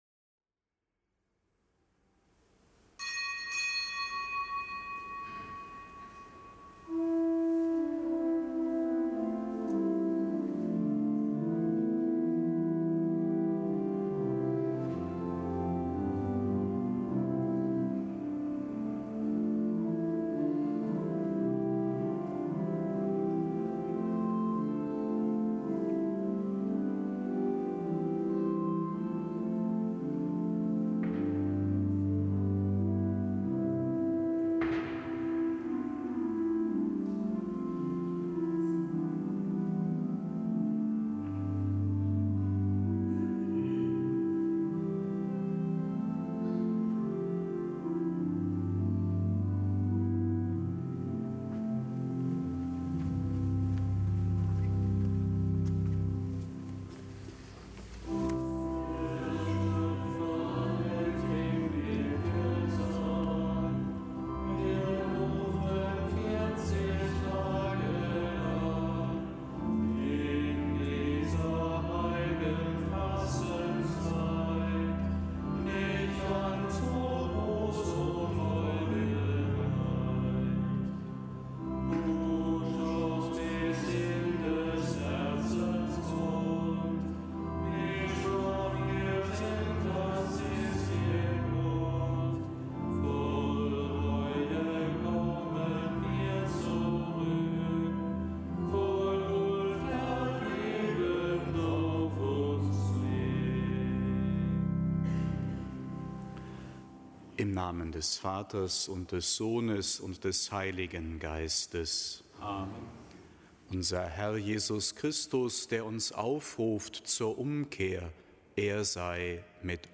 Kapitelsmesse aus dem Kölner Dom am Dienstag der zweiten Fastenwoche.